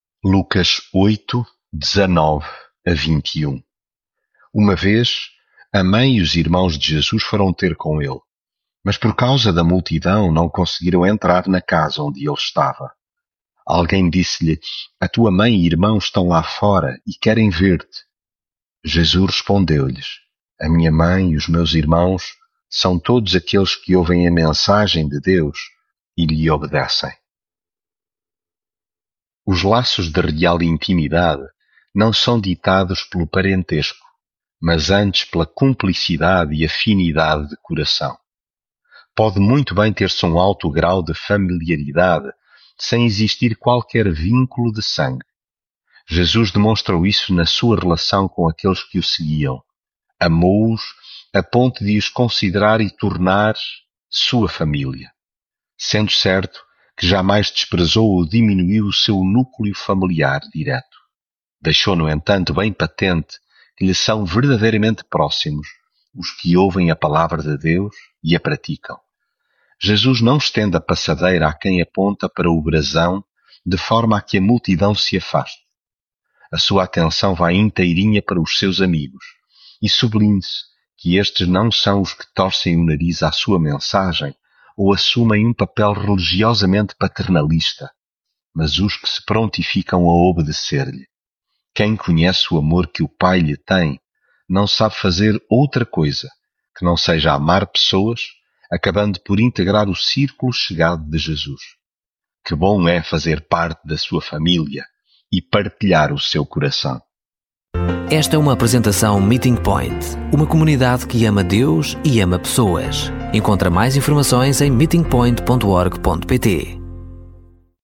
devocional Lucas leitura bíblica Uma vez, a mãe e os irmãos de Jesus foram ter com ele, mas por causa da multidão não conseguiram entrar...